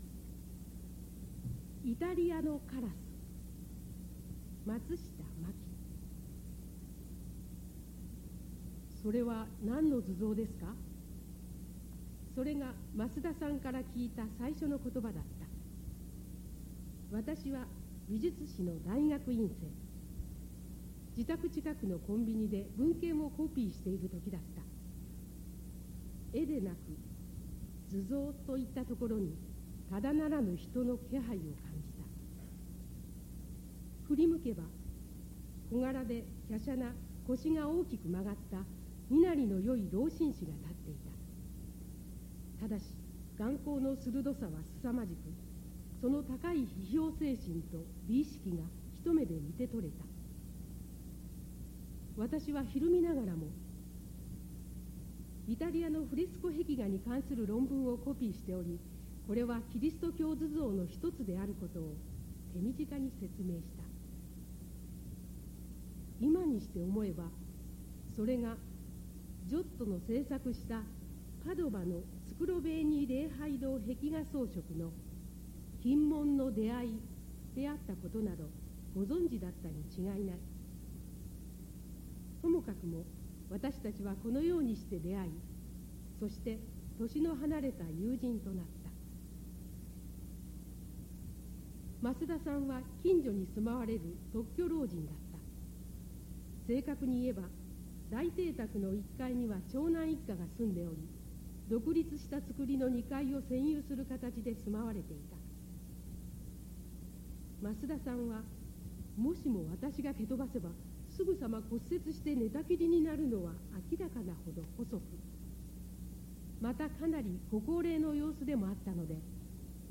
最優秀賞作品の朗読音声
〈朗読〉音訳ボランティアグループ サークルさえずりの皆さん